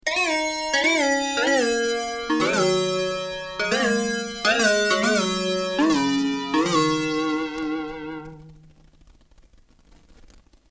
A great and unique "revival" soundbank including typical but original digital FM synthesis patches - Click here to read a detailed patches description
IMPORTANT NOTE: slight external reverb and chorus FXs have been added for the MP3 demos